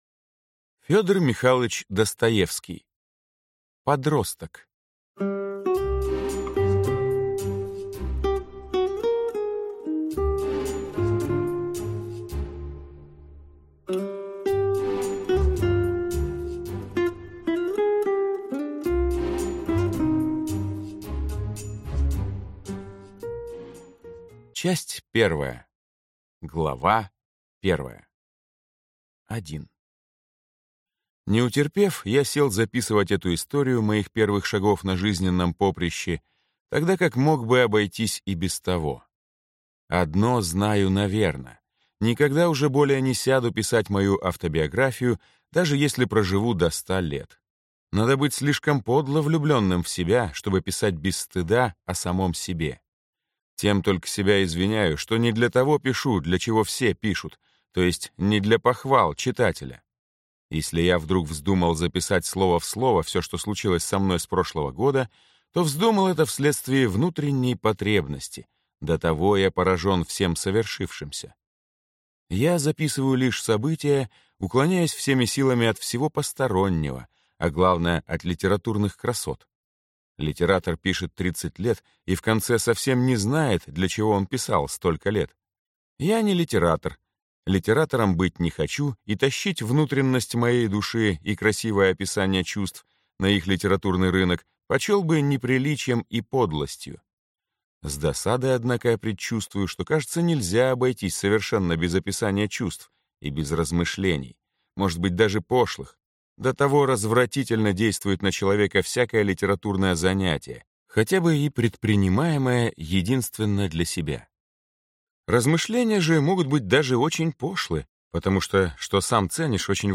Аудиокнига Подросток | Библиотека аудиокниг